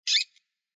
PixelPerfectionCE/assets/minecraft/sounds/mob/rabbit/idle3.ogg at mc116